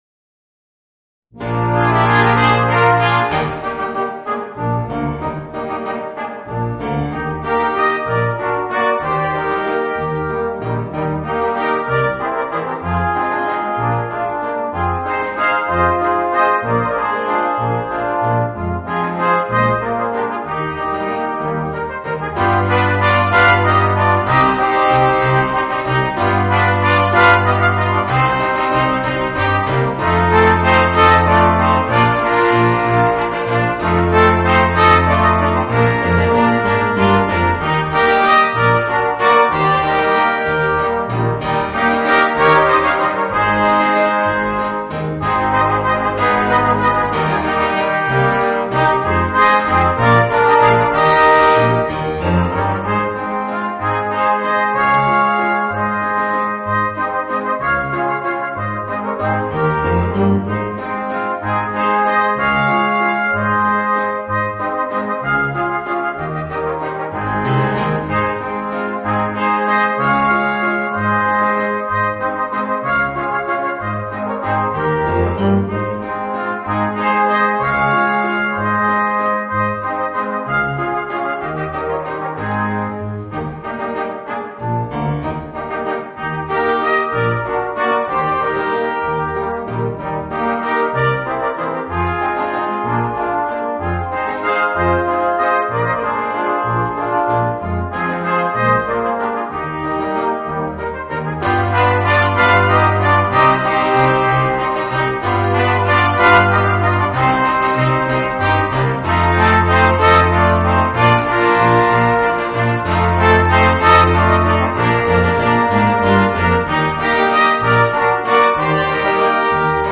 Voicing: 3 Trumpets, Horn and Trombone